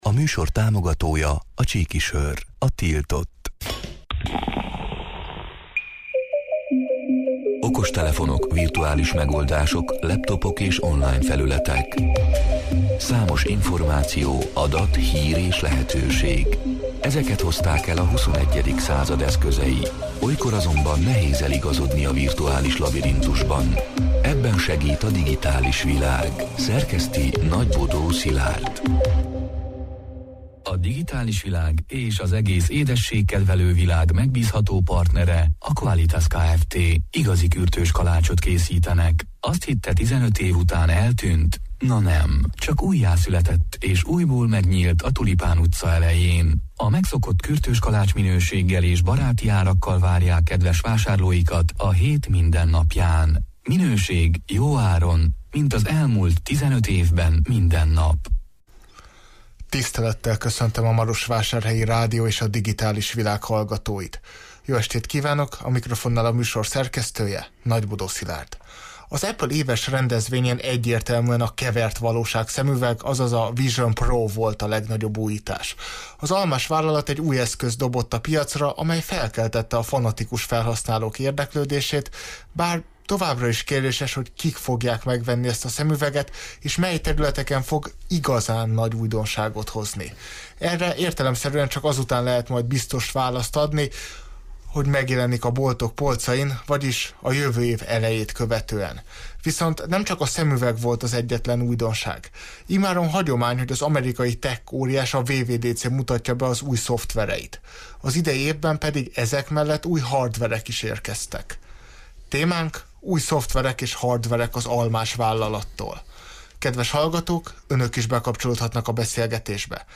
A Marosvásárhelyi Rádió Digitális Világ (elhangzott: 2023. június 20-án, kedden este nyolc órától élőben) c. műsorának hanganyaga: Az Apple éves rendezvényén egyértelműen a kevert valóság szemüveg, azaz a Vision Pro volt a legnagyobb újítás.